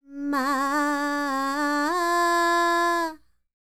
QAWALLI 06.wav